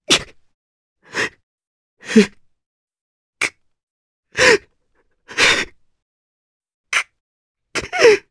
Dimael-Vox_Sad_jp.wav